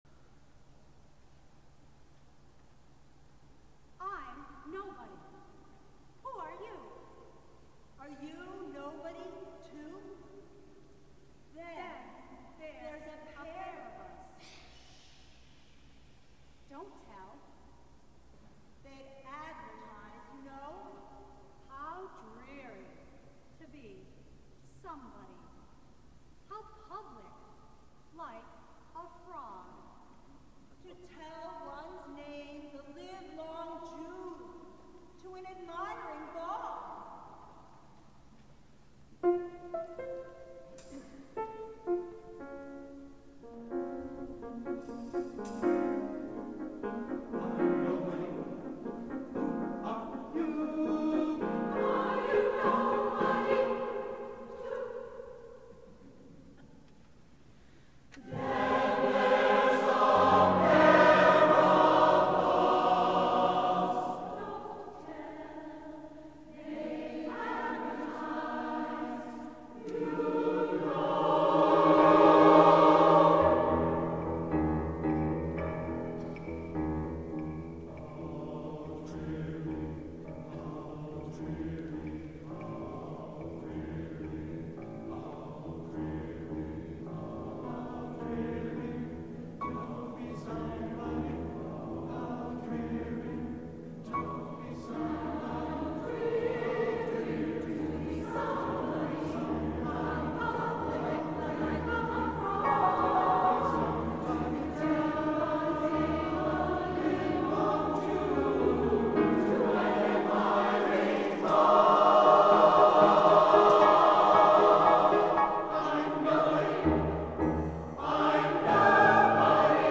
for SATB Chorus, Piano, and Opt. Percussion (2006)